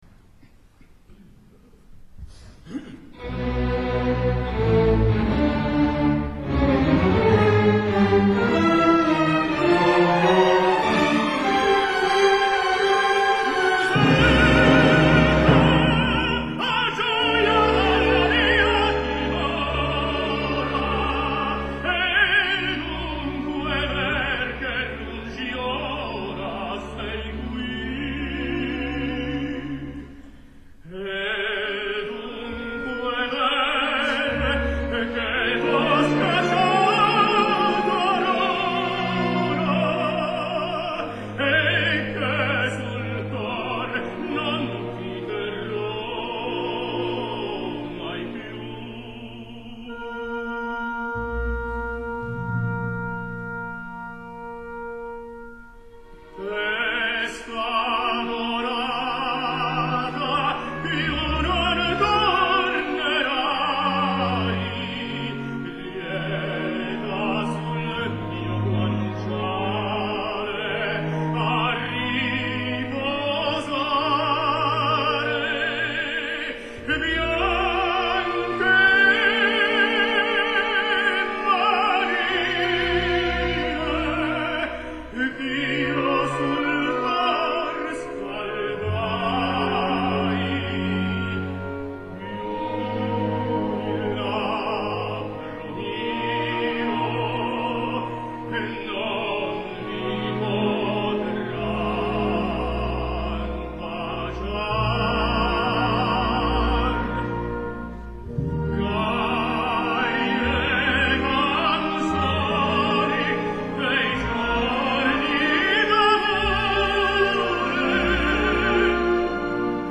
Roberto Alagna, tenor
Recital in Aarhus (Danemark)
Aarhus Symphony Orchestra
Seguidamet un altre ària verista, la “Testa adorata” de La Boheme de Leoncavallo, que ja tocaria veure al Liceu, és clar que si no ens fan Puccini com ens han de fer un Leoncavallo que no sigui I Pagliacci, que es preveu aviat. L’Alagna canta al límit i es nota.